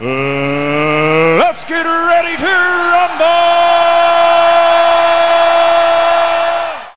Звуки для троллинга